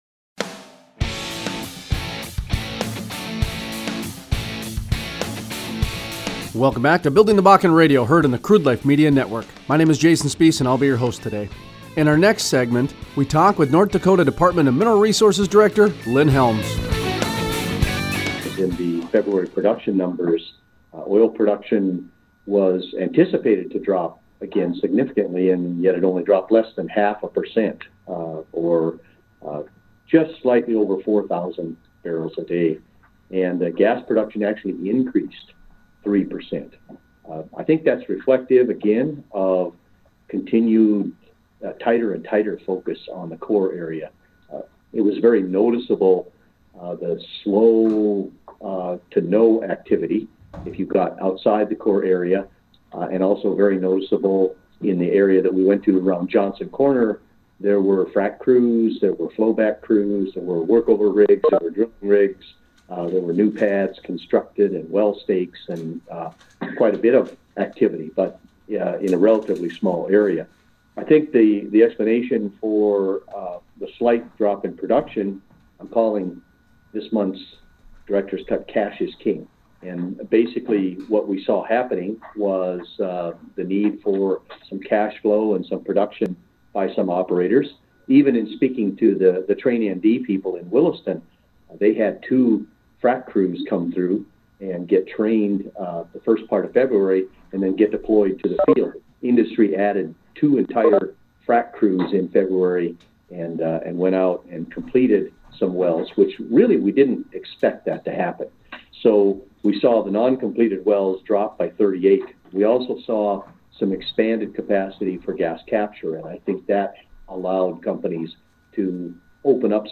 Interviews: Lynn Helms, director, ND Department of Mineral Resources Gives an update on production numbers, flaring and rigs in the Bakken. His theme for this interview is Cash is King.